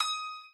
admin-fishpot/b_piano2_v127l8-3o7dp.ogg at main